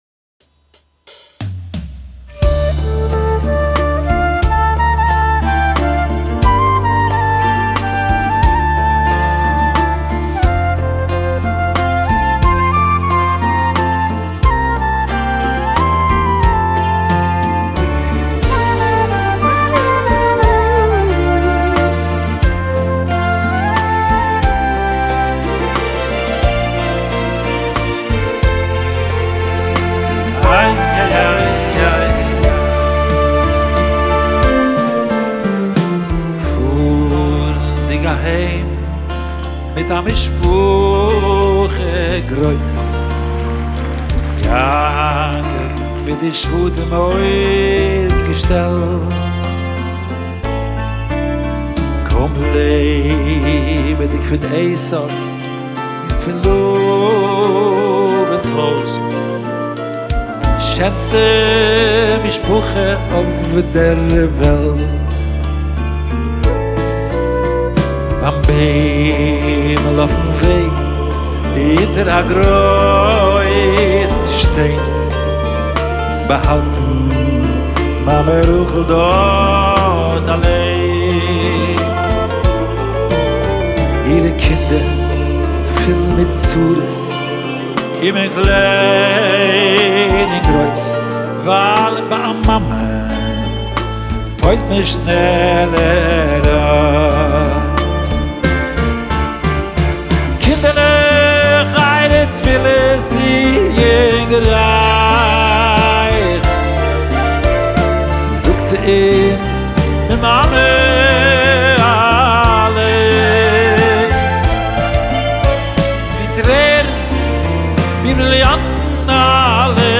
בביצוע לייב מרגש
מול קהל מצומצם באווירה חמה ומלאת רגש